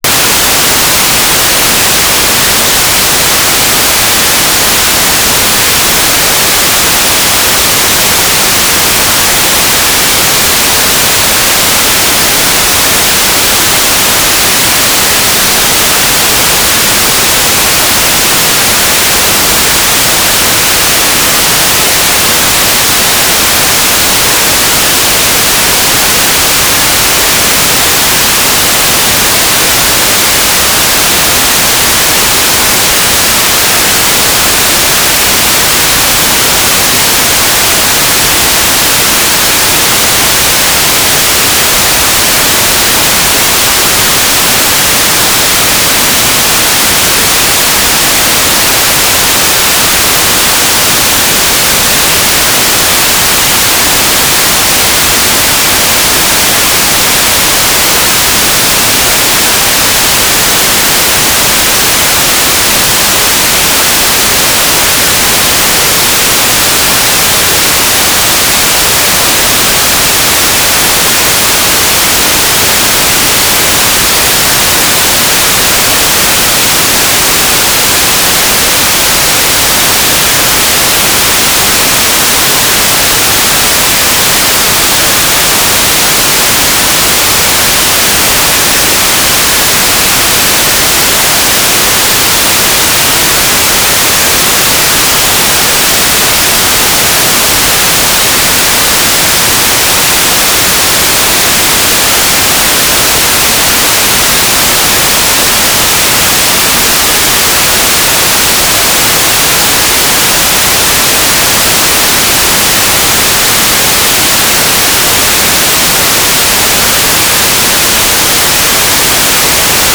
"transmitter_description": "Telemetry",
"transmitter_mode": "FM",